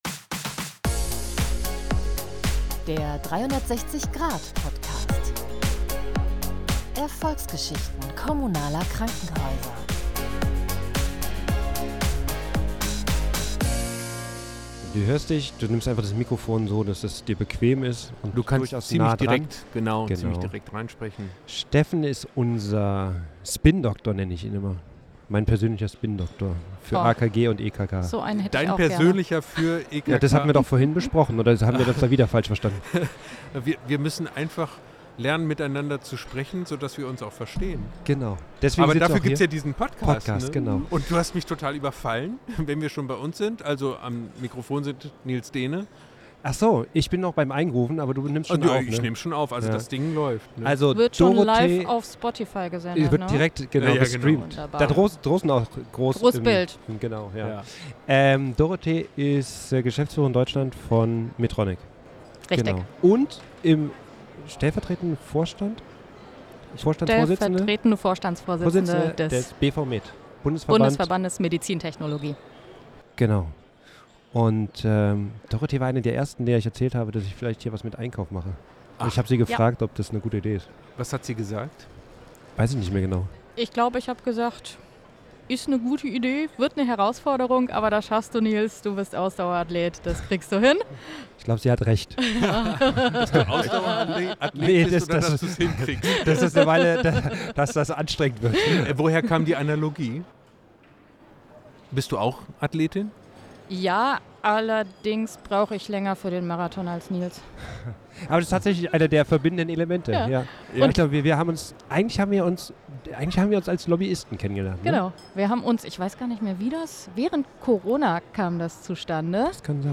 Aufgenommen wurde Folge 10 des 360-Grad-Podcasts am Randes des diesjährigen DRG-Forums in Berlin.